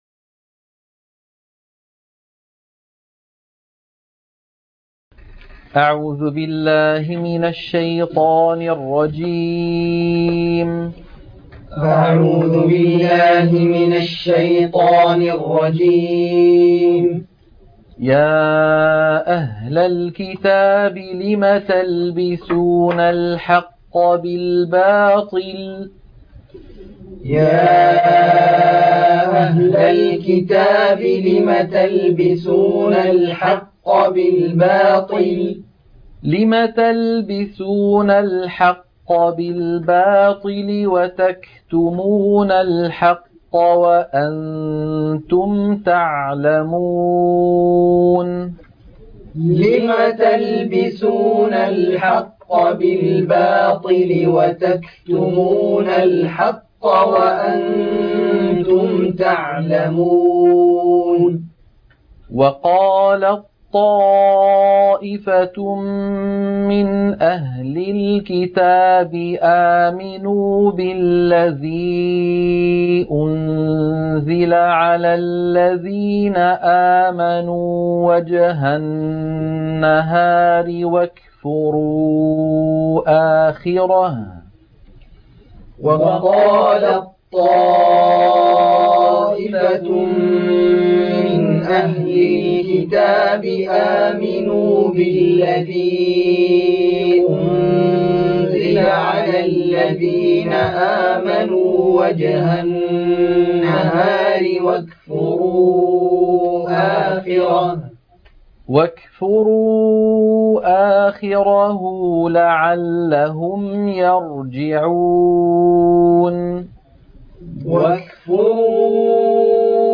عنوان المادة تلقين سورة ءال عمران - الصفحة 59 - التلاوة المنهجية